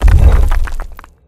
02_gimmick_door_up_01.wav